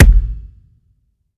drum-hitnormal.mp3